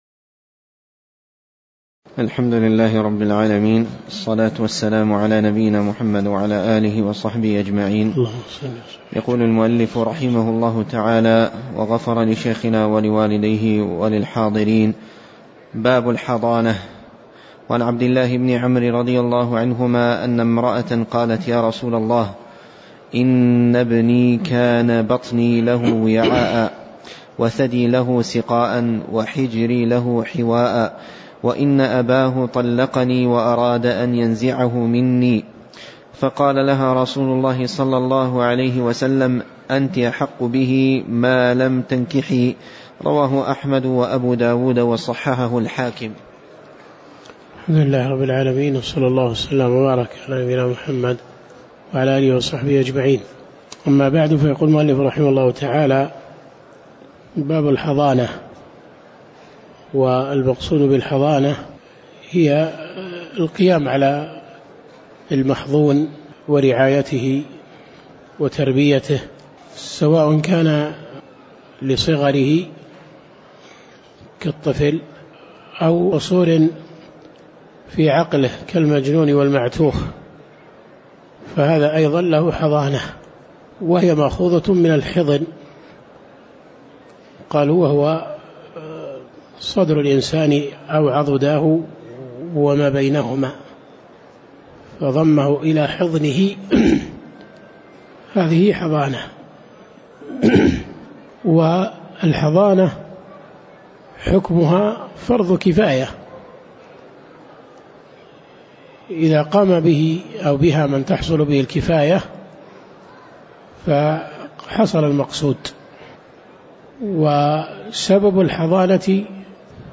تاريخ النشر ١ ربيع الثاني ١٤٣٩ هـ المكان: المسجد النبوي الشيخ